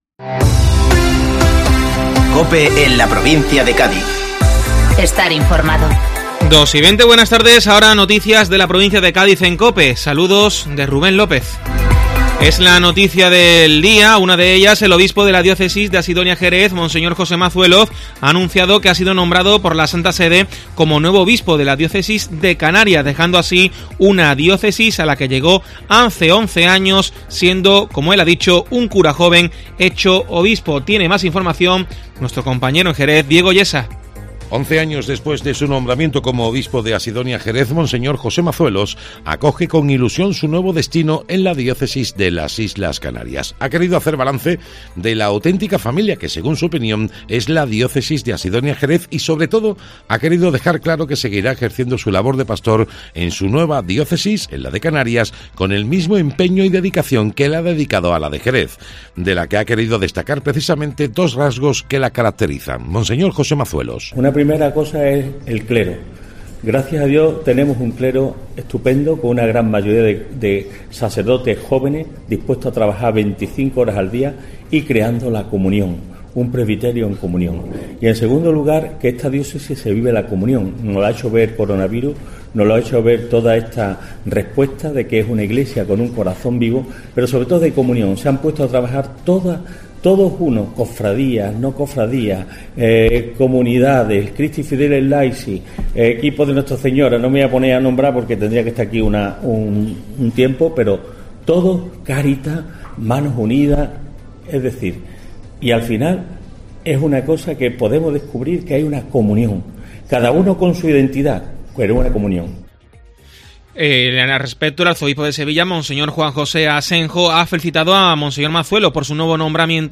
Informativo Mediodía COPE Provincia de Cádiz (6-7-2020)